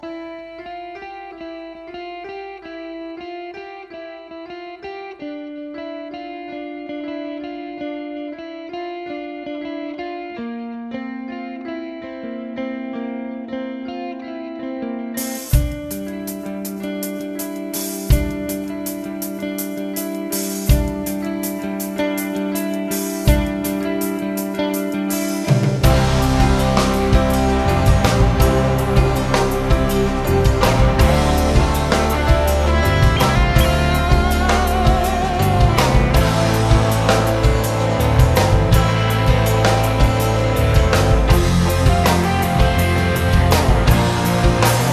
Italian prog band
melodic progressive concept albums